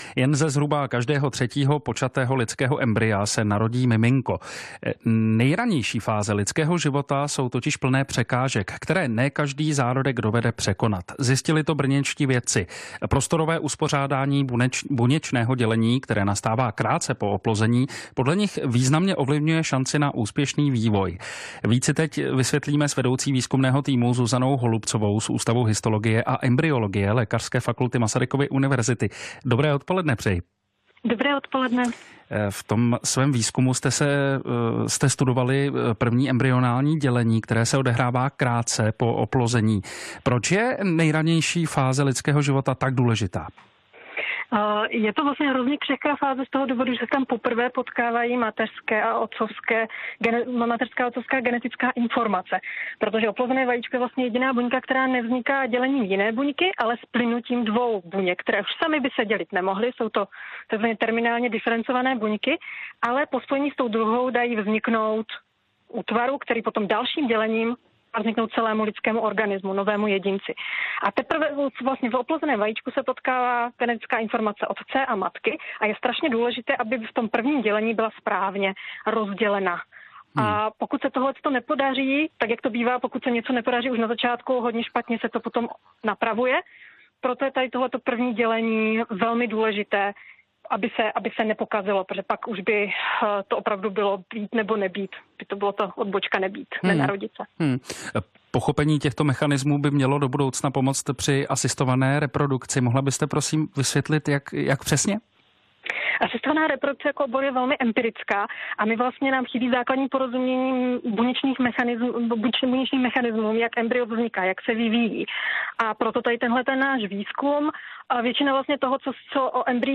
o precizní medicíně ve vysílání Českého rozhlasu Plus v pořadu Věda plus.